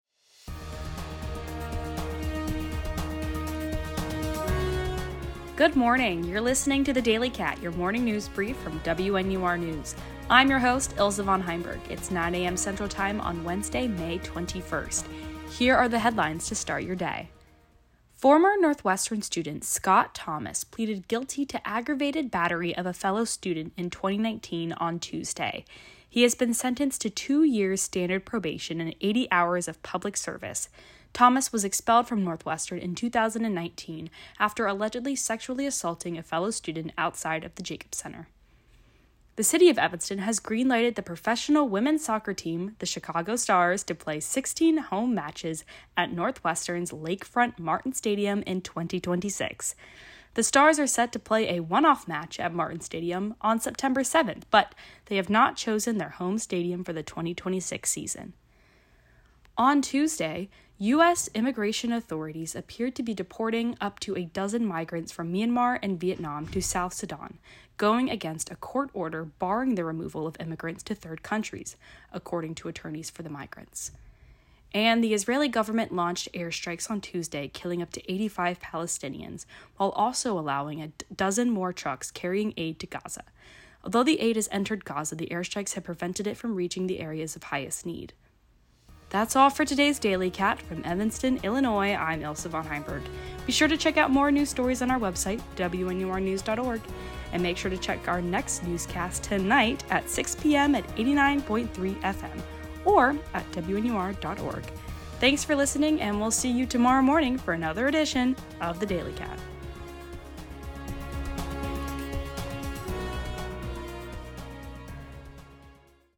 DATE: May 21, 2025 Northwestern student charged, Northwestern arrest, Israel airstrike, Gaza aid, deportations to South Sudan, Chicago Stars, Martin Stadium. WNUR News broadcasts live at 6 pm CST on Mondays, Wednesdays, and Fridays on WNUR 89.3 FM.